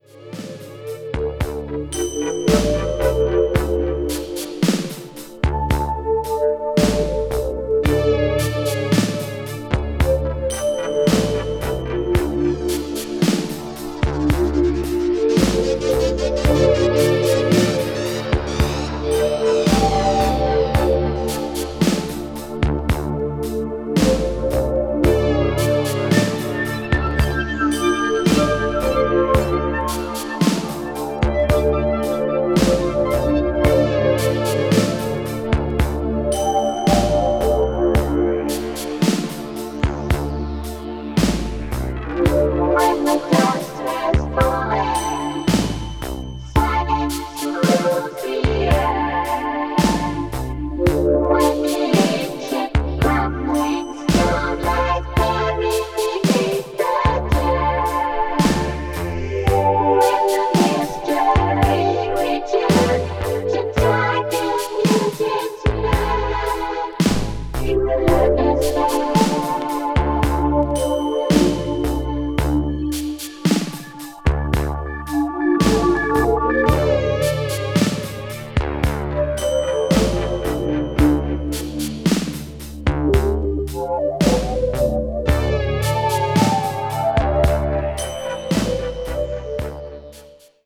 media : EX/EX(わずかにチリノイズが入る箇所あり)
electro   electronic   hi-nrg   synth disco   synthesizer